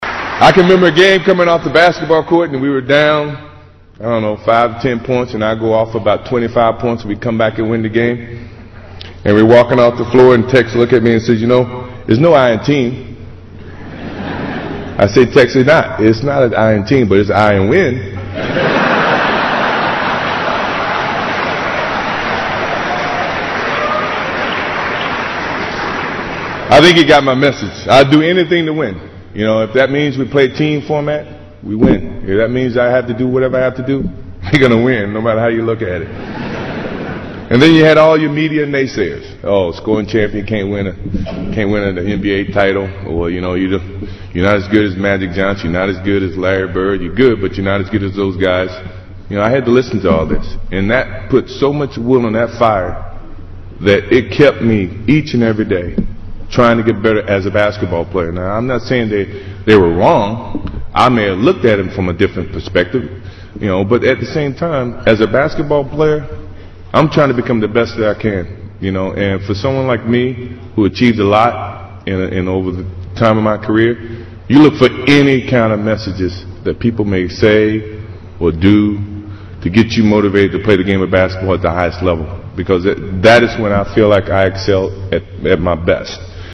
偶像励志英语演讲 第76期:不说绝不(10) 听力文件下载—在线英语听力室
在线英语听力室偶像励志英语演讲 第76期:不说绝不(10)的听力文件下载,《偶像励志演讲》收录了娱乐圈明星们的励志演讲。